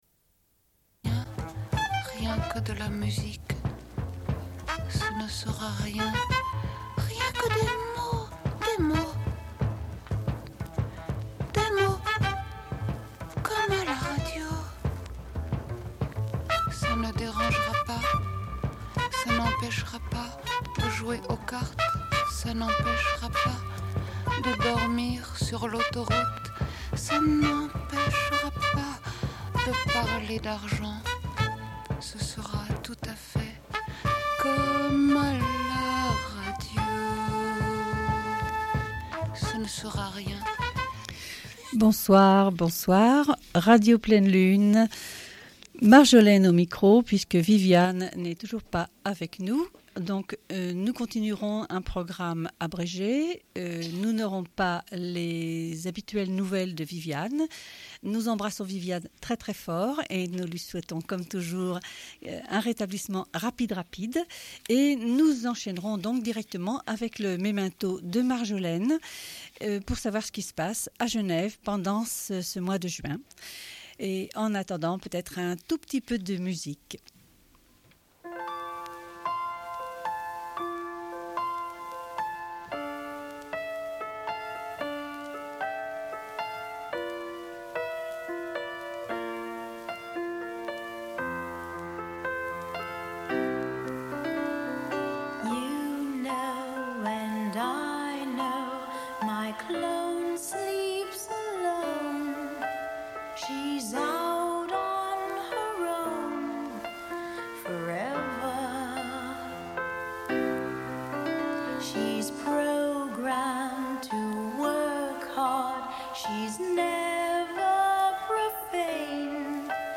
Bulletin d'information de Radio Pleine Lune du 23.06.1993 - Archives contestataires